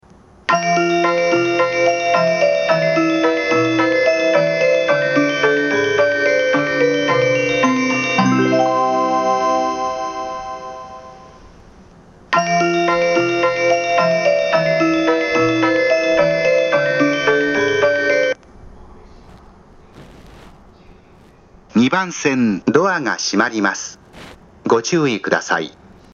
大宮方面の始発列車の方が鳴りやすいですが、余韻が長いので余韻切りが多いです。
新宿駅2番線